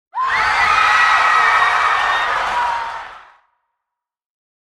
Crowd Panic Sound Effect for Horror Scenes
Realistic crowd panic sound effect with men and women screaming in fear. Perfect for horror scenes, disaster videos, emergency simulations, and intense film moments. High-quality audio of mass panic and chaos.
Crowd-panic-sound-effect.mp3